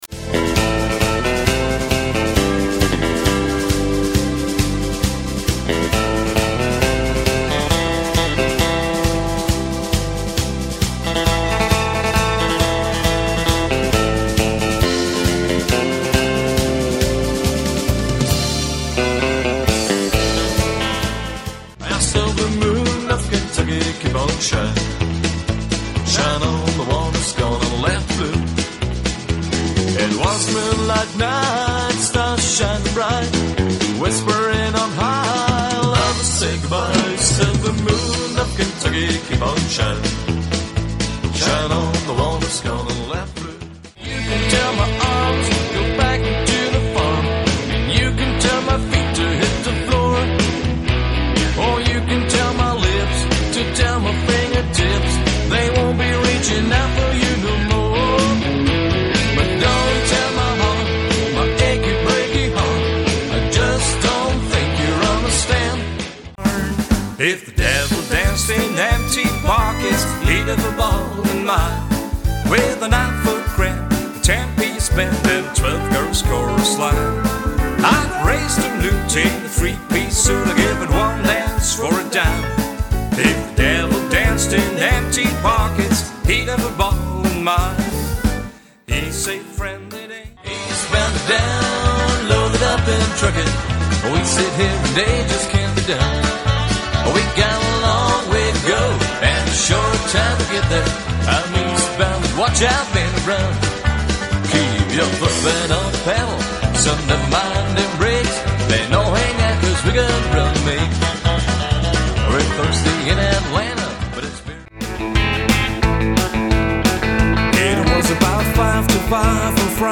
Alles in bester Sound-Qualität und 100 % Live !